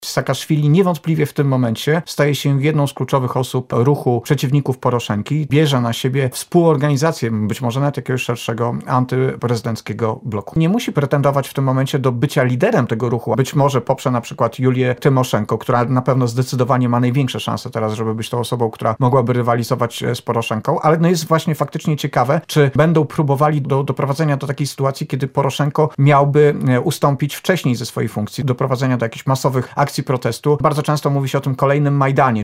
Dziennikarz skomentował w ten sposób dla Radia Lublin wczorajsze wydarzenia na polsko-ukraińskiej granicy.